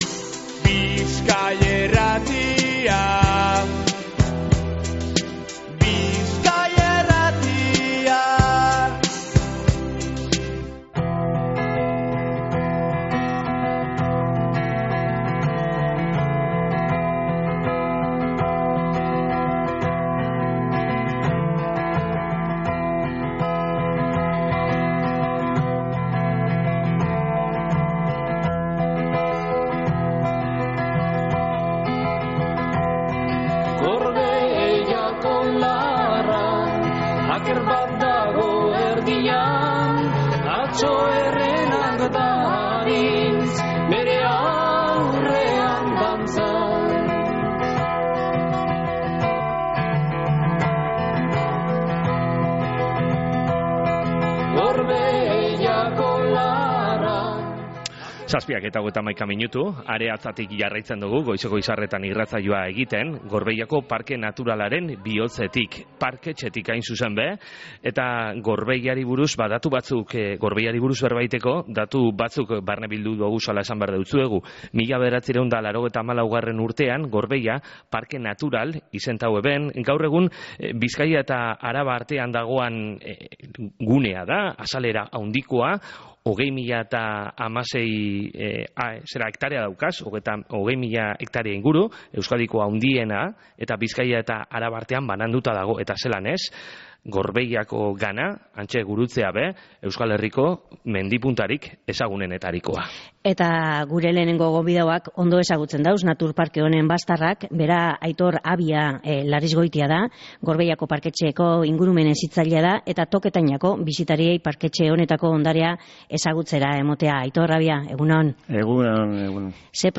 Gorbeiako Parke Naturaleko parketxetik egin dogu Goizeko Izarretan irratsaioa